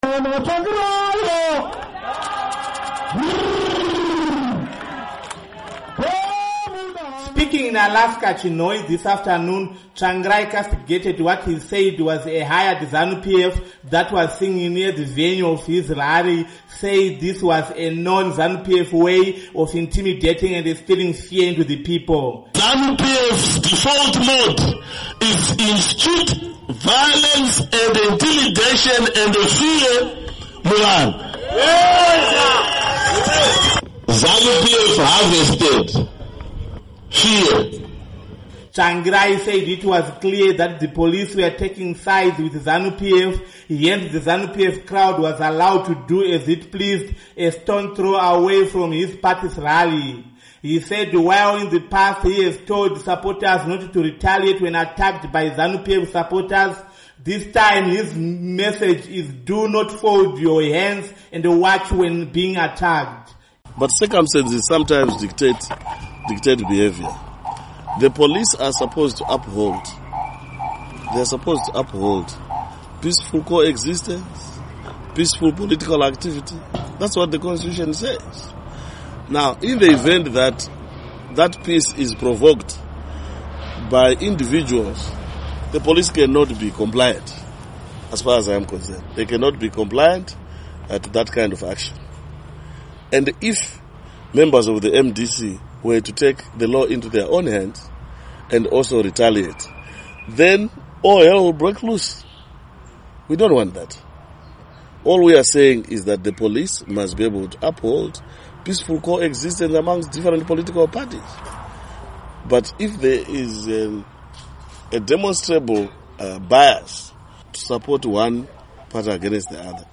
Report on Tsvangirai's Alaska, Chinhoyi, Rally